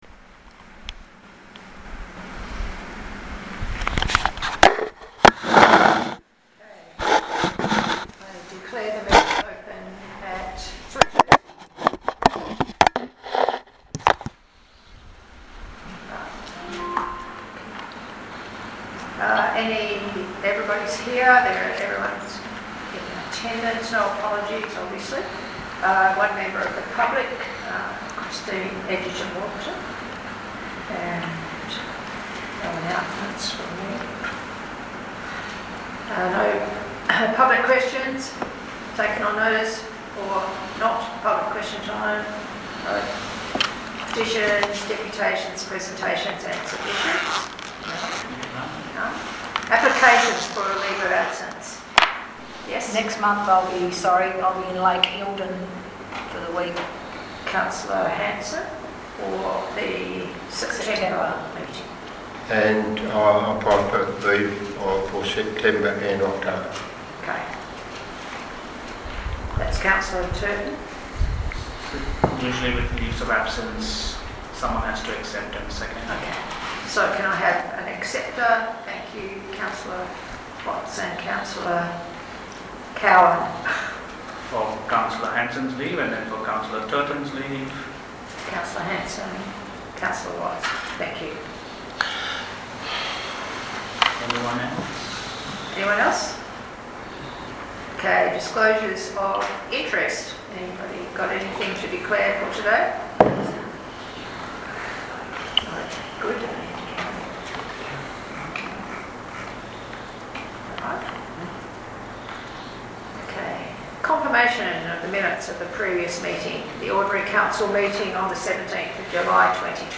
august-2025-ordinary-council-meeting-recording.mp3